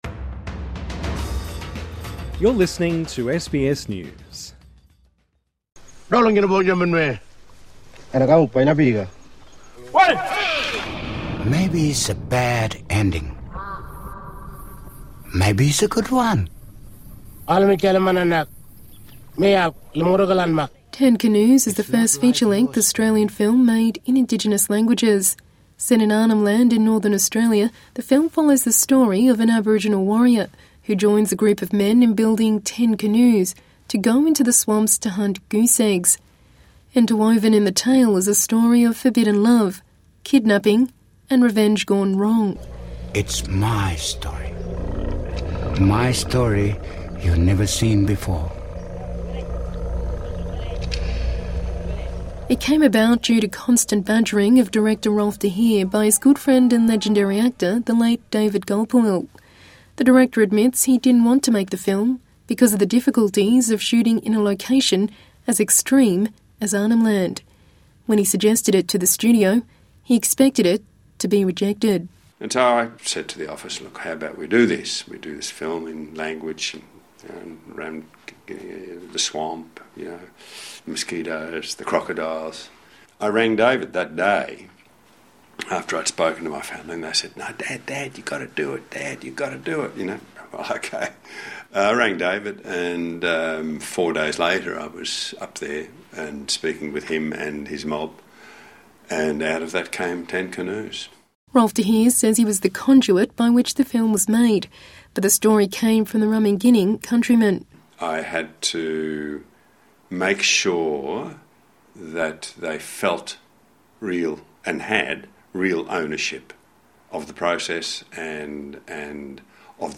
Ten Canoes and Rabbit Proof Fence are both considered milestones of Australian cinema; and are about to be re-released after being digitally remastered. Both movies, of course, tell First Nations stories - and their directors say the films have left a legacy well beyond the movie house. And a warning to our Aboriginal and Torres Strait Islander viewers that this report contains images and voices of someone who has died.